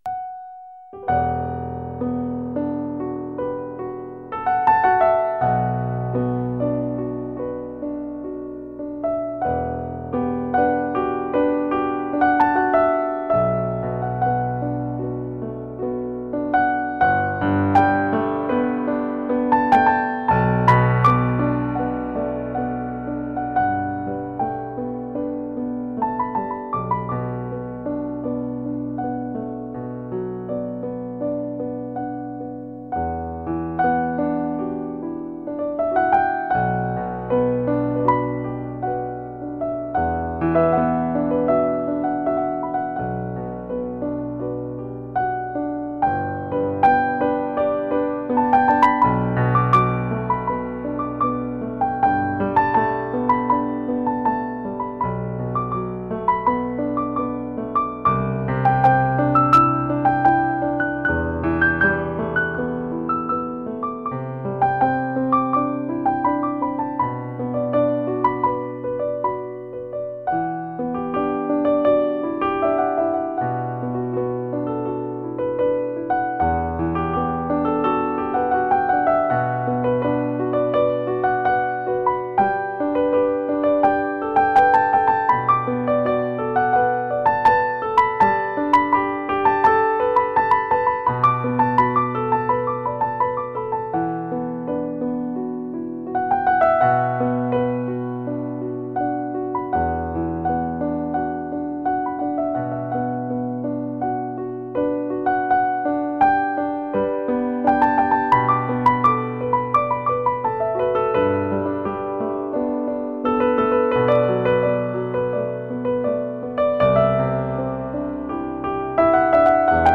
Stage of Contact improvisation, February 7th: improvisation.